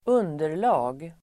Uttal: [²'un:der_la:g]